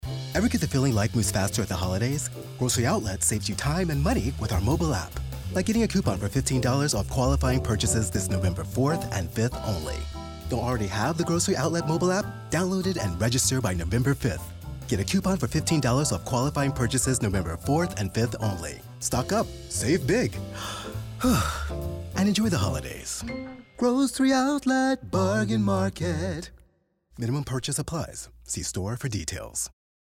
Commercial Work
Studio: Sennheiser Microphone
Grocery-Outlet-East-App-15-Coupon-INSTORE.mp3